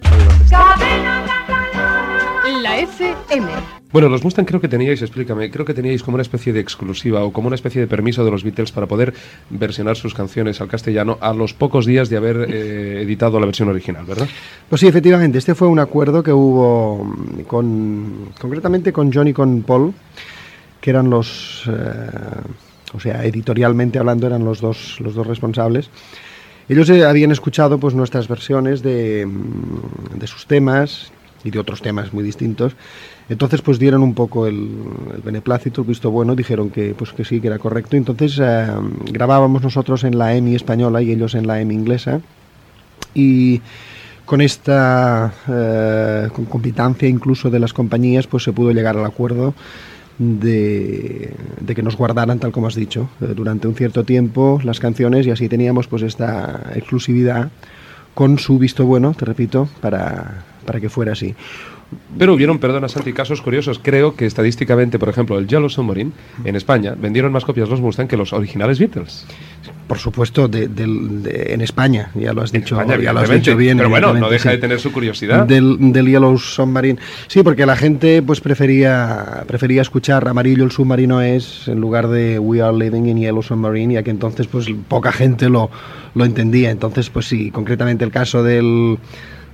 Entrevista
Musical